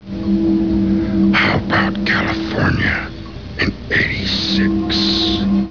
Then Scorpion would taunt Sting with long, drawn out monologues that made no sense. Maybe WCW should have spent less on that voice box Ole Anderson used while doing the Scorpions voice and forked out some money on some script writers.
Even better than a garbled voice is the fact that The Scorpion performed BLACK MAGIC.